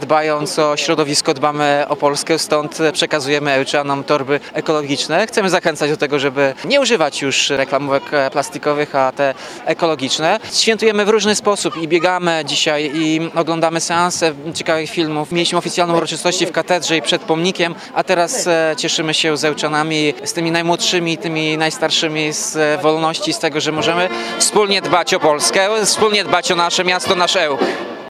Mieszkańcy otrzymali od prezydenta miasta ekologiczne torby. – To sposób na nowoczesny patriotyzm – mówił Tomasz Andrukiewicz.